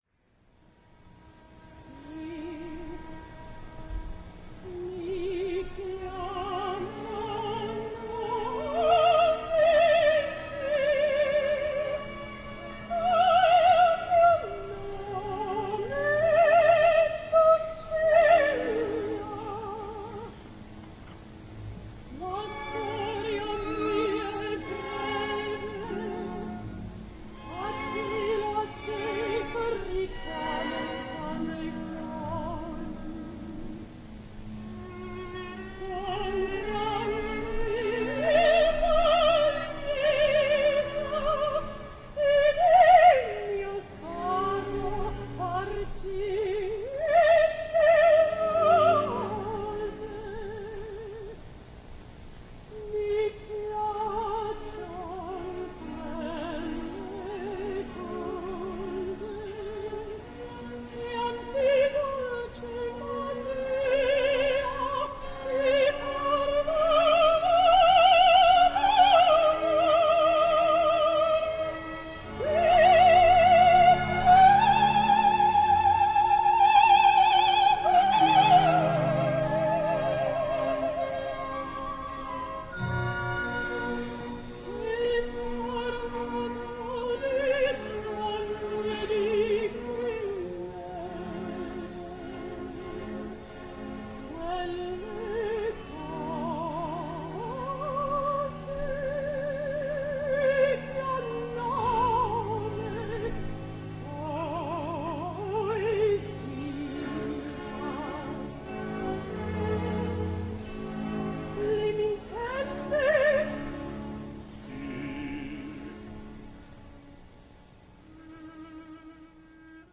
Soprano
recorded live